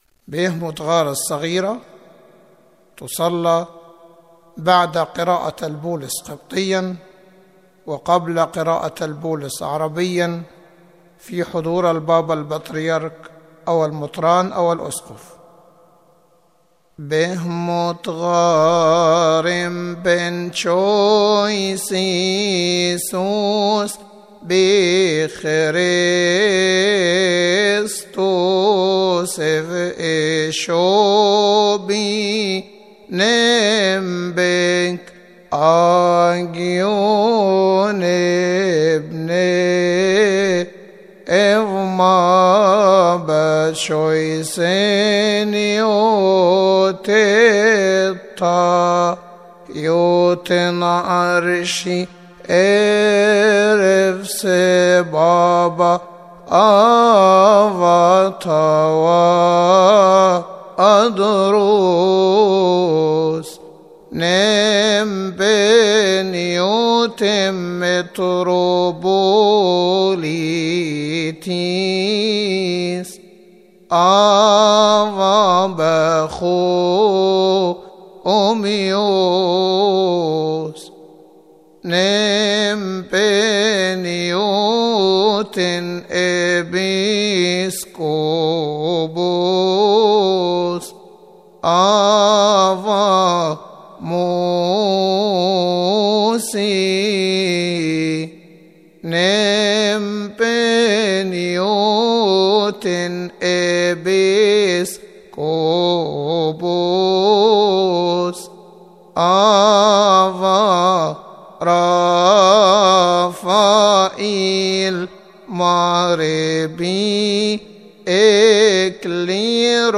4.-Pi-ehmot-ghar-the-small-hymn-for-the-Patriarch-and-the-Bishop..mp3